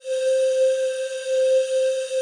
PAD 48-3.wav